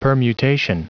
Prononciation du mot permutation en anglais (fichier audio)